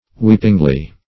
weepingly - definition of weepingly - synonyms, pronunciation, spelling from Free Dictionary Search Result for " weepingly" : The Collaborative International Dictionary of English v.0.48: Weepingly \Weep"ing*ly\, adv. In a weeping manner.
weepingly.mp3